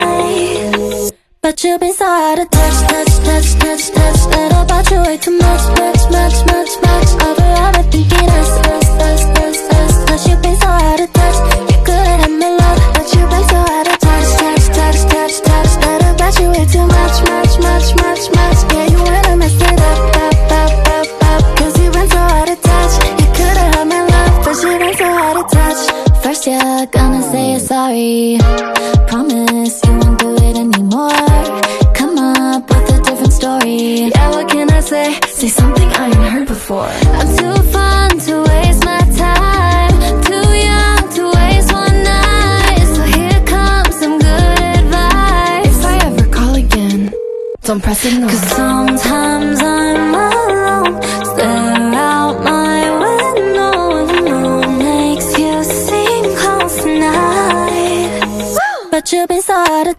Italian opera piece